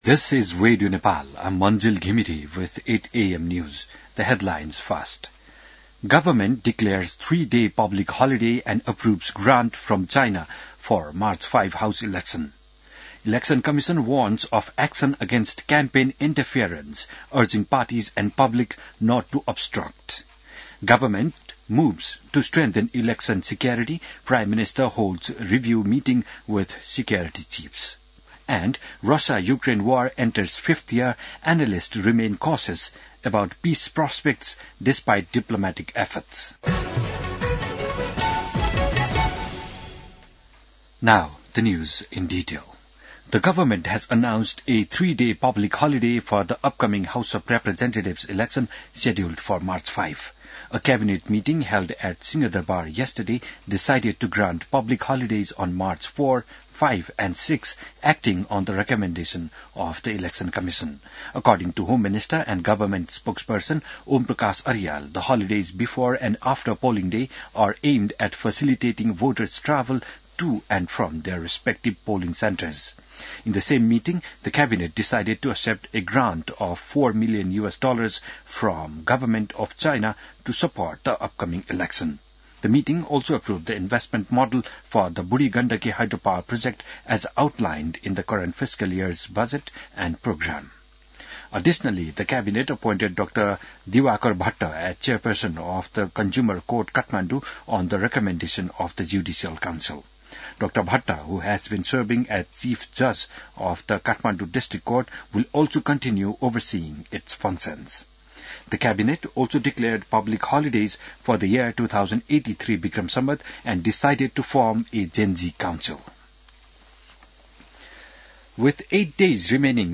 बिहान ८ बजेको अङ्ग्रेजी समाचार : १३ फागुन , २०८२